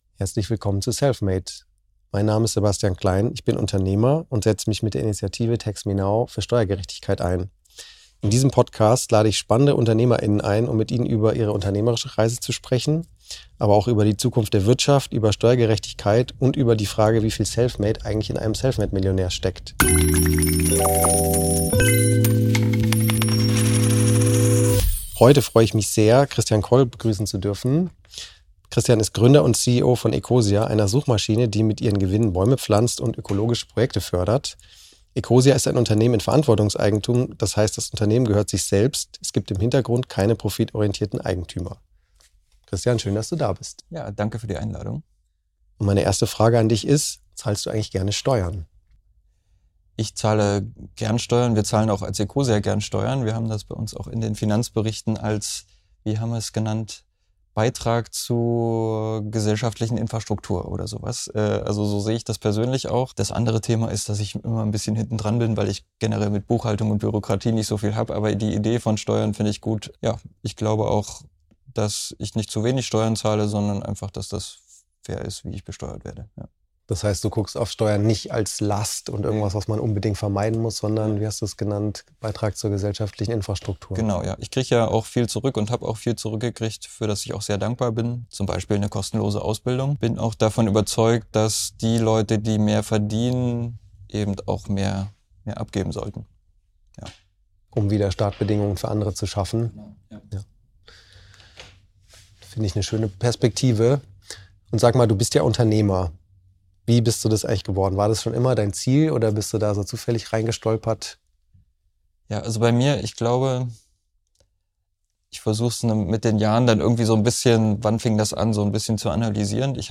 Ein Gespräch über Verantwortung, Chancengleichheit und darüber, warum Umverteilung kein Angriff auf Leistung ist, sondern ihre Grundlage.